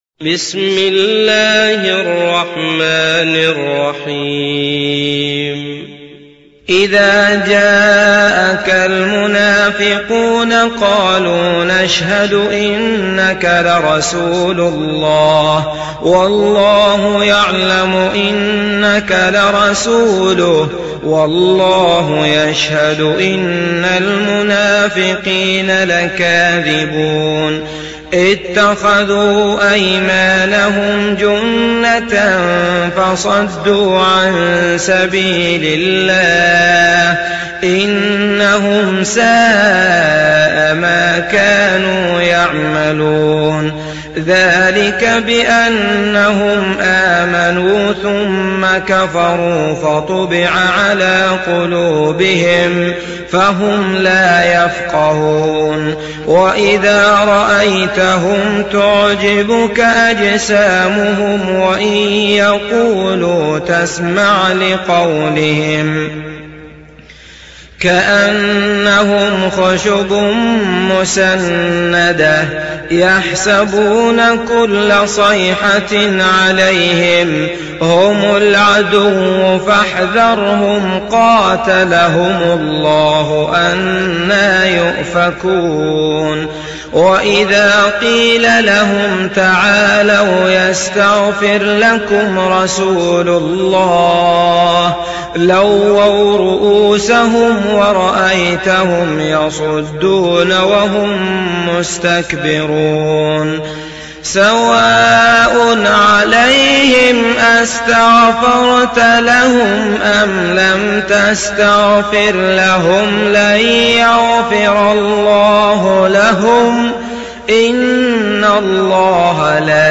دانلود سوره المنافقون mp3 عبد الله المطرود روایت حفص از عاصم, قرآن را دانلود کنید و گوش کن mp3 ، لینک مستقیم کامل